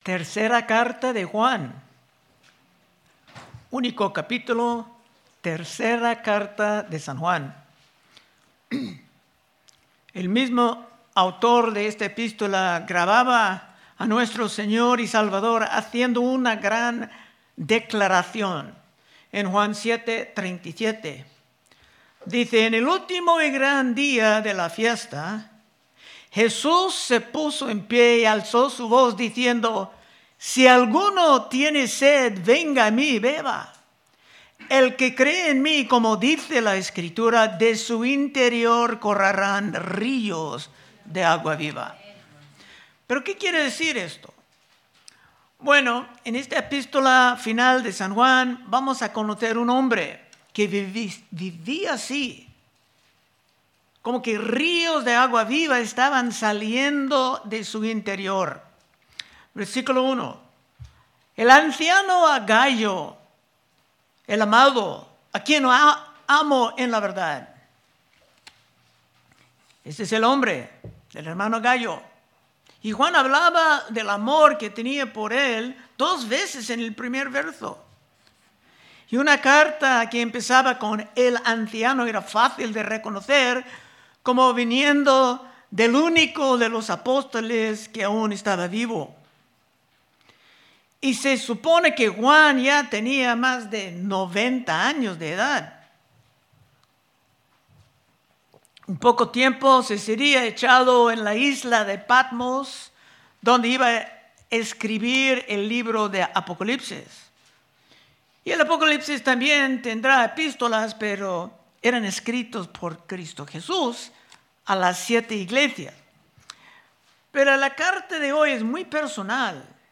Predicaciones De Exposición Libro De 3 Juan